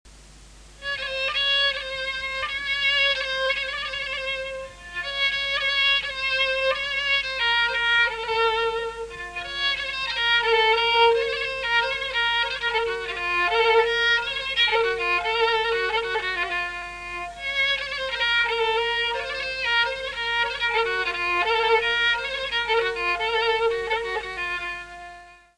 Instruments traditionnels arméniens
Kemenche
Le kemenche (Kemaentche, Kamancha) est un violon à base en forme de pointe. On le joue dressé sur le genou avec un archet de crin de cheval, tenu serré avec la main tout en jouant.
kemenche.mp3